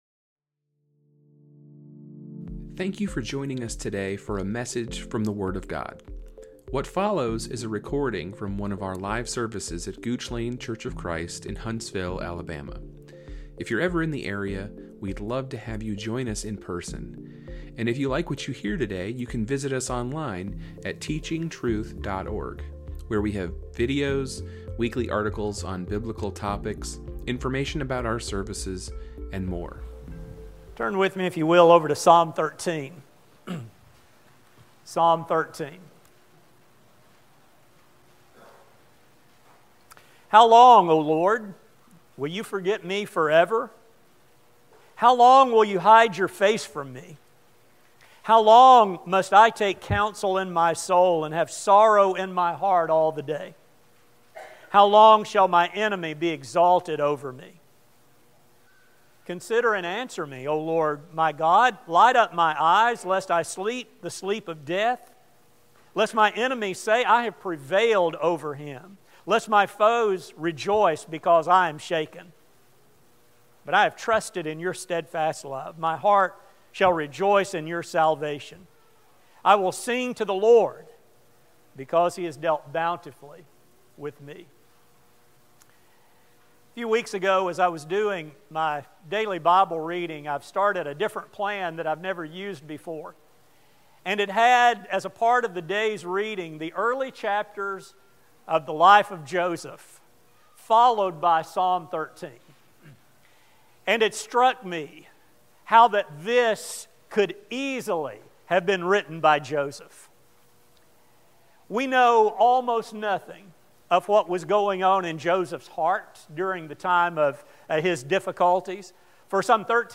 This study will explore how David dealt with the dilemma he faced and how we can learn from the lessons he recorded for us. A sermon